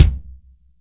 Index of /90_sSampleCDs/AKAI S6000 CD-ROM - Volume 3/Drum_Kit/DRY_KIT2
TIGHT KIK1-S.WAV